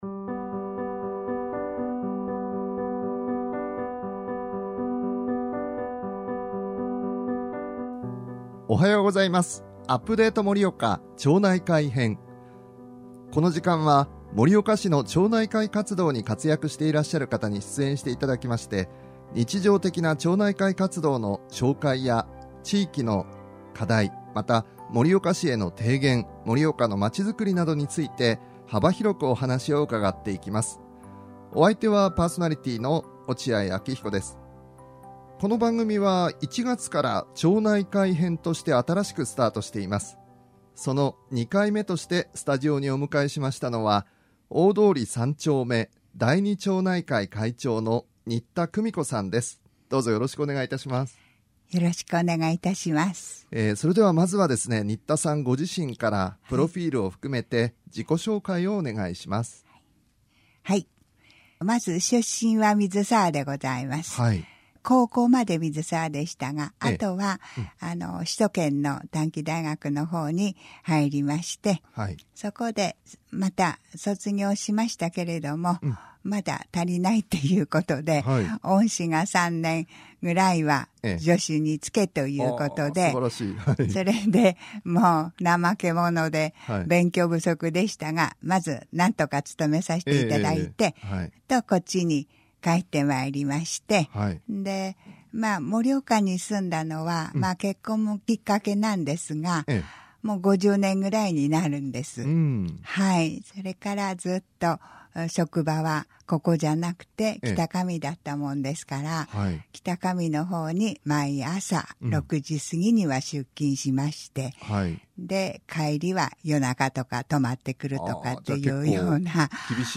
盛岡市議会議員の皆さんが盛岡のまちづくり・生活・未来などを語ります。